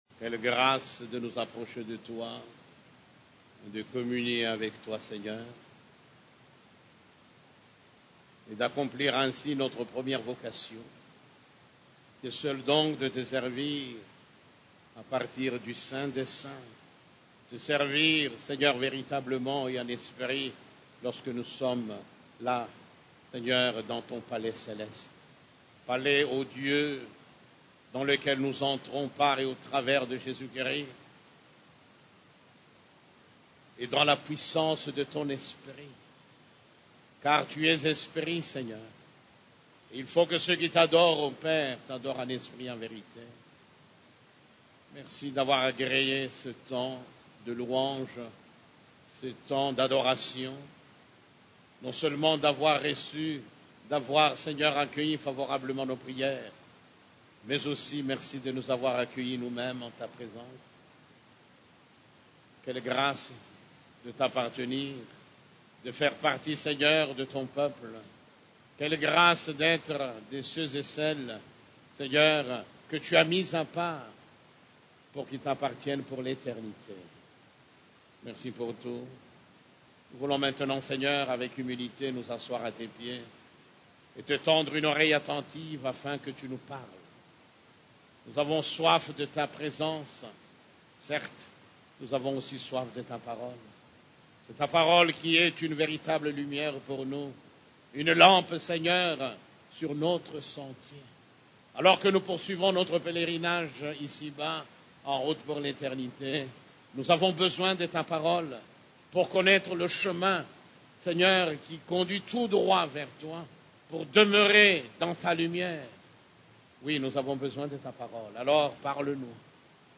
CEF la Borne, Culte du Dimanche, L'entrée du lieu secret 5